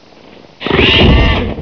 The following sounds are from all the Alien movies made to date.
Alien snort